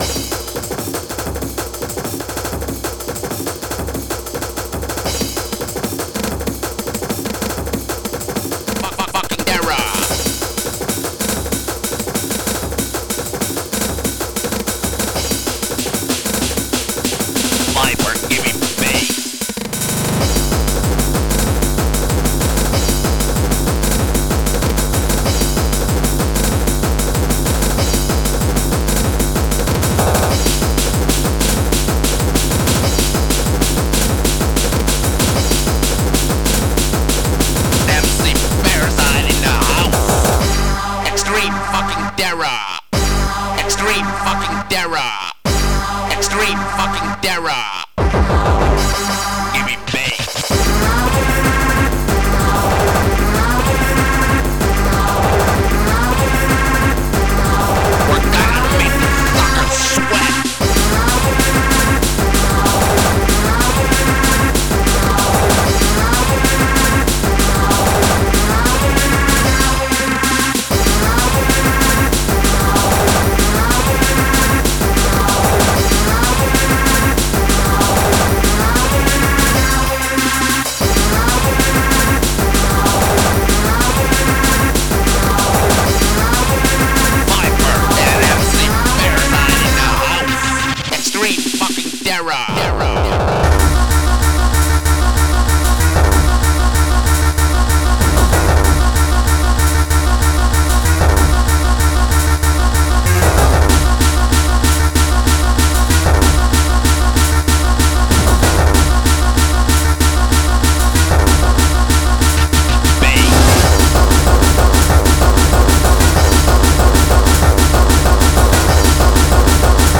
xm (FastTracker 2 v1.04)
THiZ NiZe HaRDCoRe